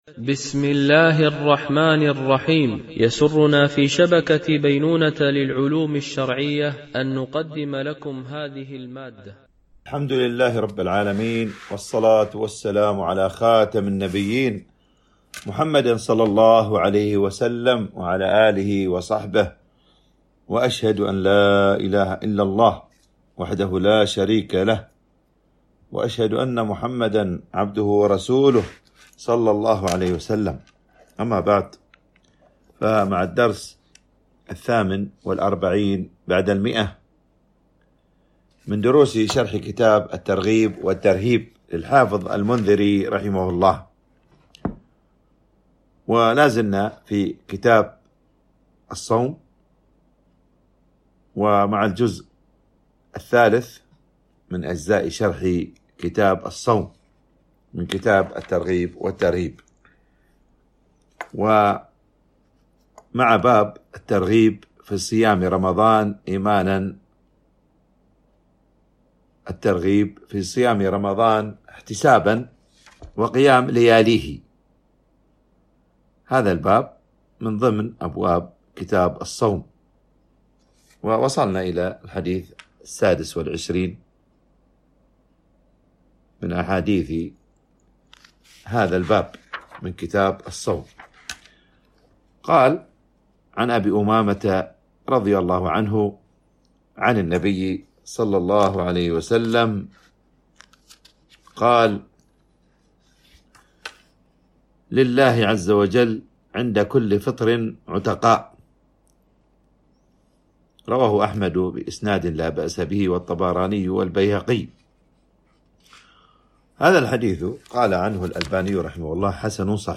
شرح كتاب الترغيب والترهيب - الدرس 148 ( كتاب الصيام ـ الجزء الثالث - باب الترهيب من إفطار شيء منه وباب صوم الست من شوال ... )